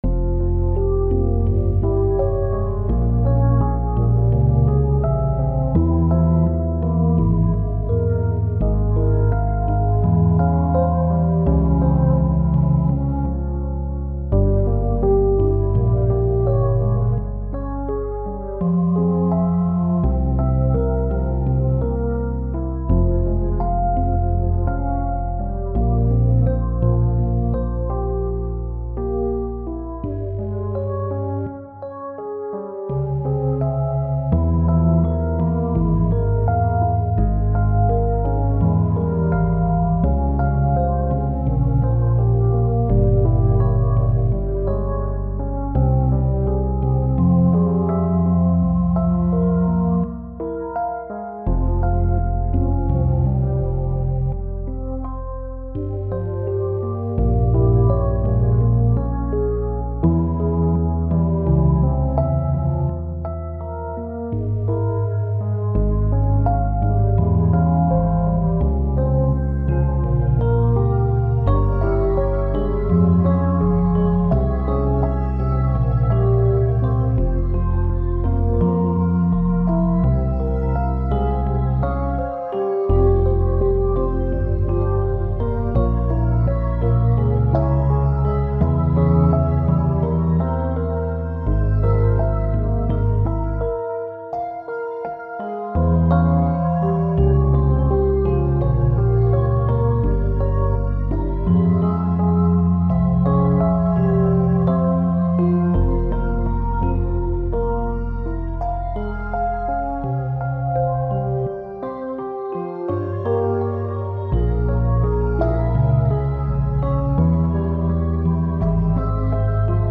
On repart sur une série "Fractale Piano".
Morse (11 - 13 ) Fa (F) Minor Harmon. 84
Plugins : MDA Piano, Organized trio, synth1